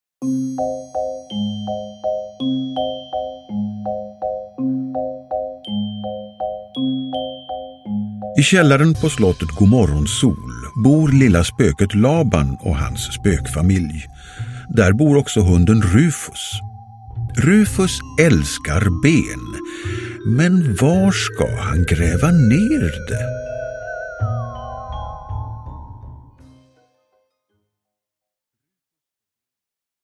Rufus och benet – Ljudbok – Laddas ner